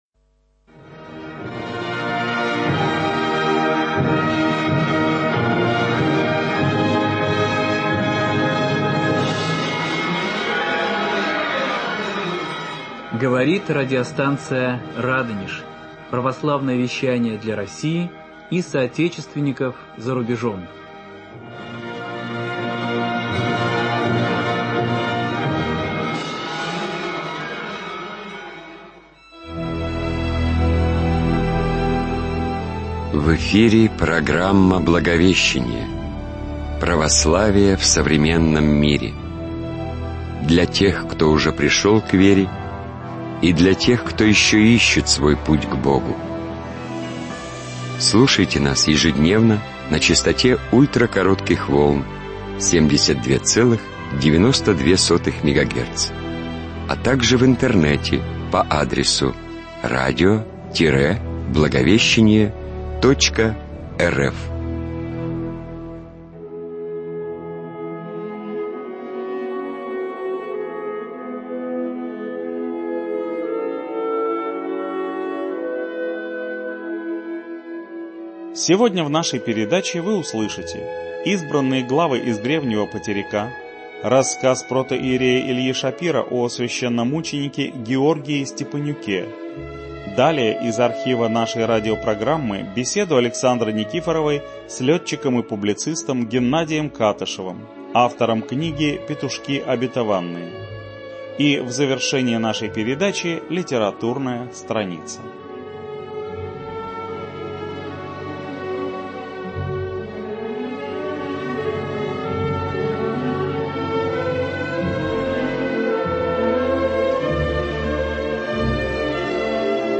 Рассказы протоиерея Алексея Лисняка читает актер